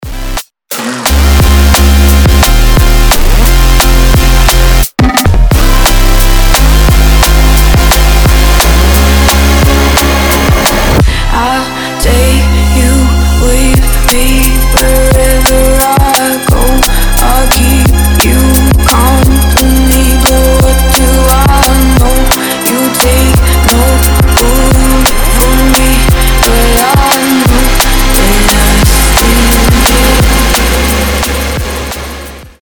• Качество: 320, Stereo
громкие
жесткие
Electronic
мощные басы
красивый женский голос
драм энд бейс